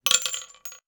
weapon_ammo_drop_24.wav